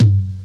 Closed Hats
TOM2.wav